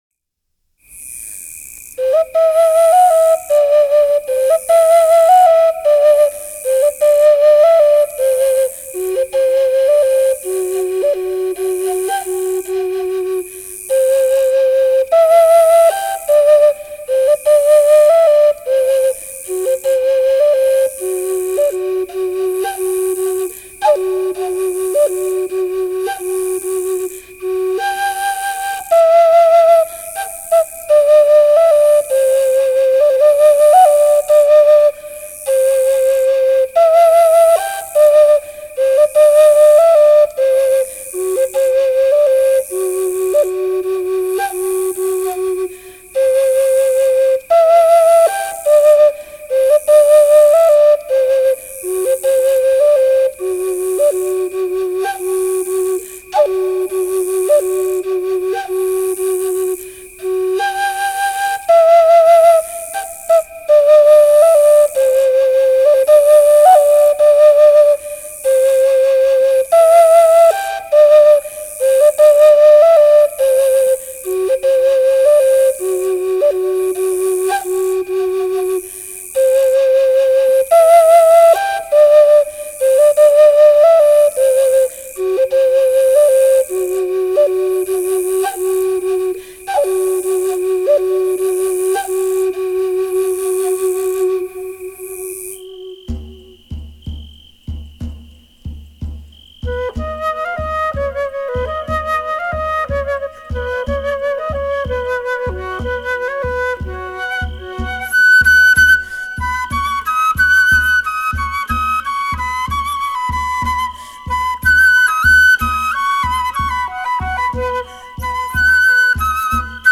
Flutist
Peaceful flute songs from around the world.